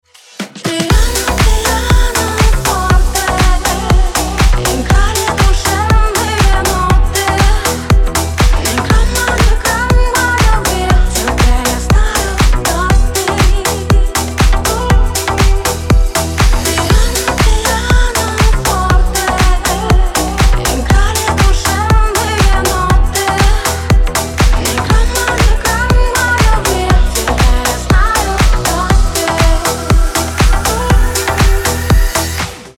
• Качество: 320, Stereo
женский голос
house
ремиксы